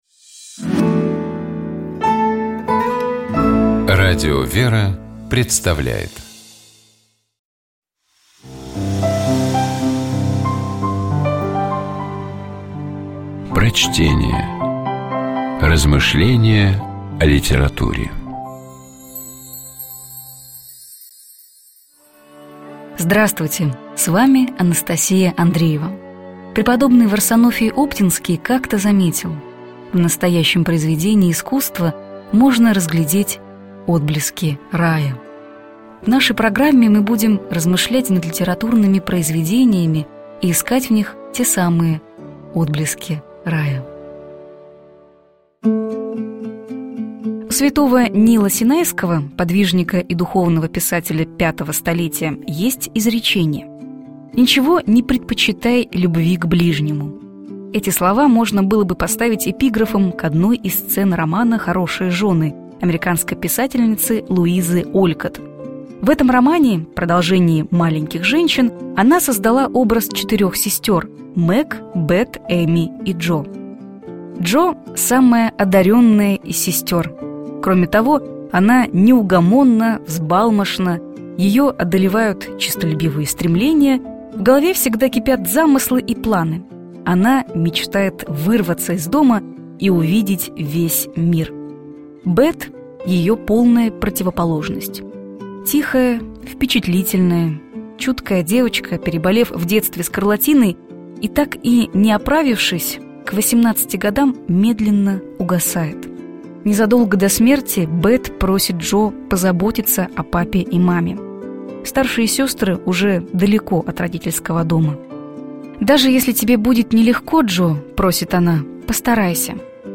На вечерних службах накануне больших церковных праздников исполняются особые церковные песнопения — великие
Вечером накануне Прощёного воскресенья, и также второго и четвёртого воскресенья Великого поста звучит великий прокимен, слова которого взяты из 68-го псалма. Давайте попробуем разобраться, о чём это песнопение, и послушаем его в исполнении сестёр Орского Иверского женского монастыря.